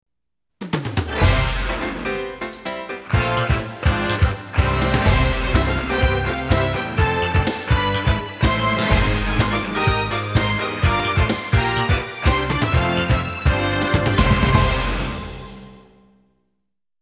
激情的背景音乐